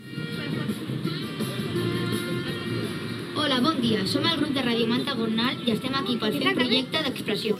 Escolar
Inici de la programació amb la identificació de la ràdio